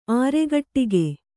♪ āregaṭṭige